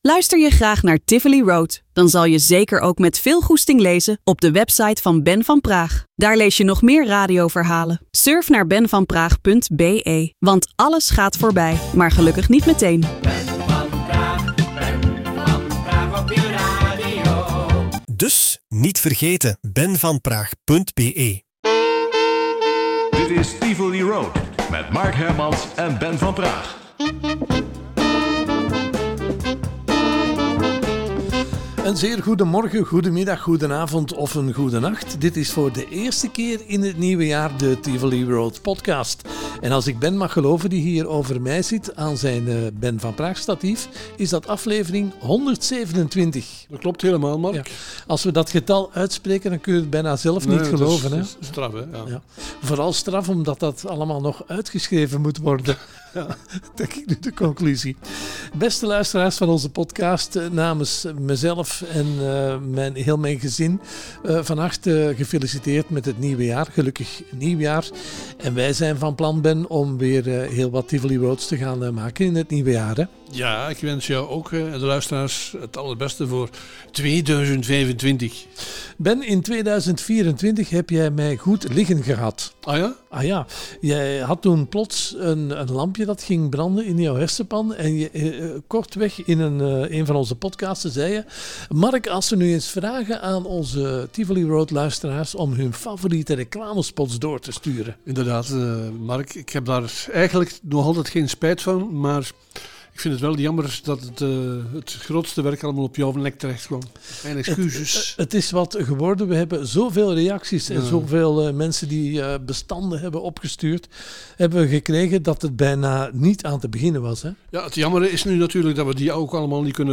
Tijdens hun wandelingen in het Tivolipark babbelen